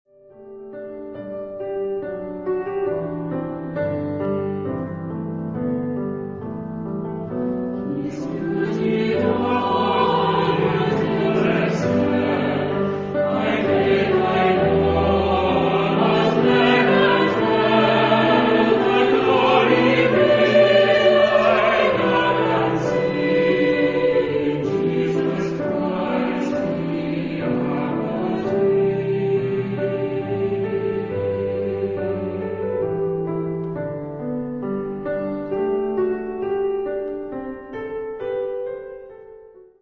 Genre-Style-Form: Sacred ; Hymn (sacred)
Mood of the piece: expressive
Type of Choir: SAH  (3 mixed voices )
Instrumentation: Piano  (1 instrumental part(s))
Tonality: G major